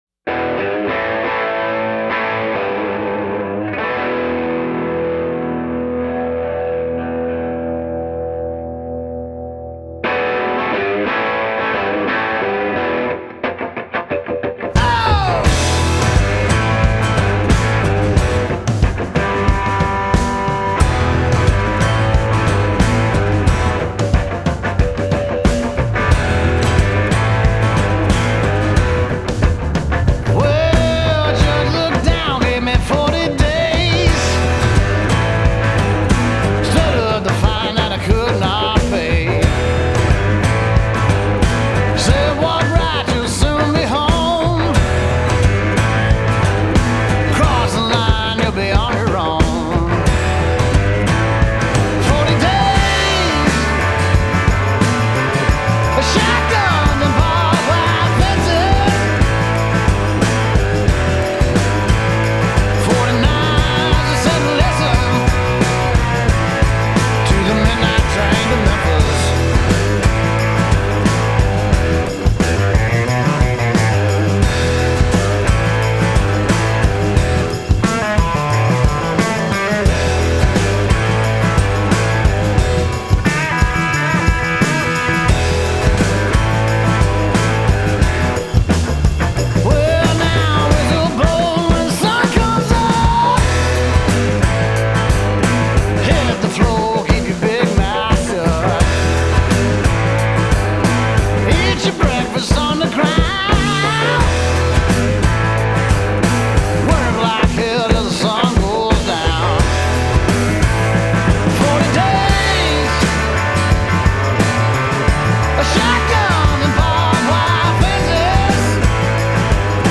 Трек размещён в разделе Зарубежная музыка / Кантри.